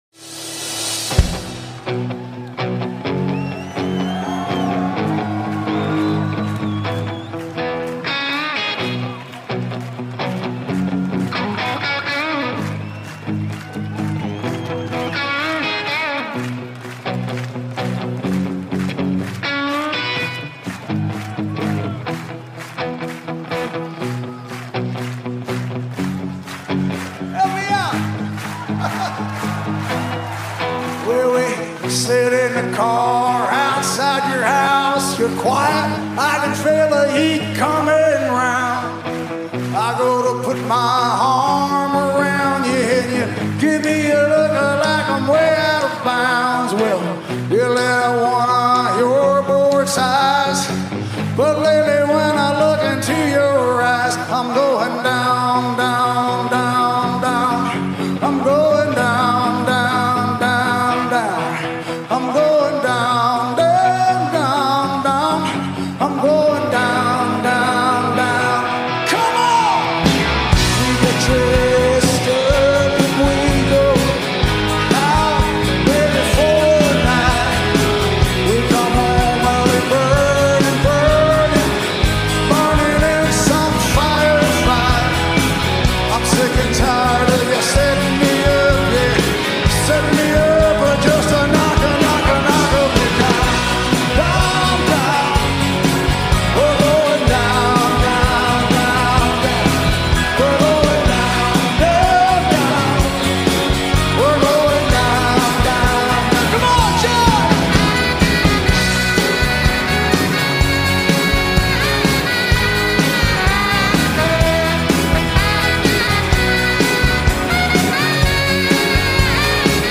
at the Hard Rock Calling festival in London, 2013.